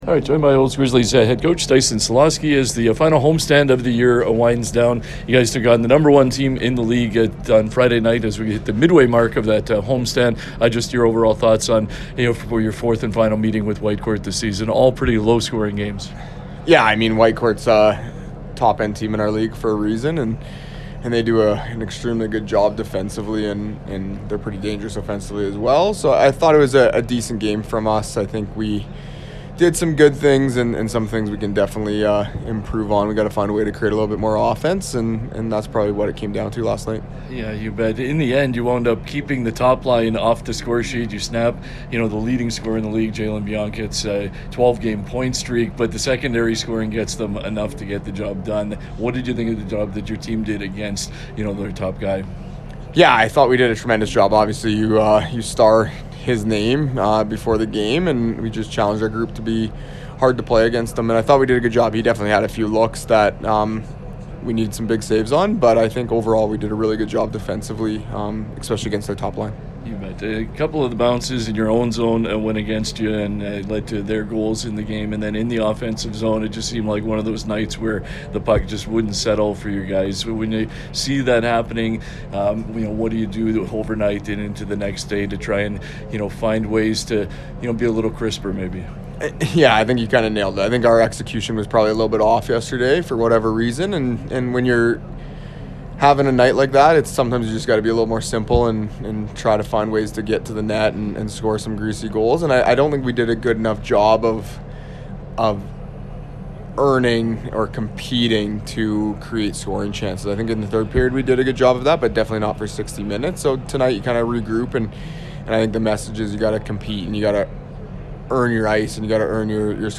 pre-game conversation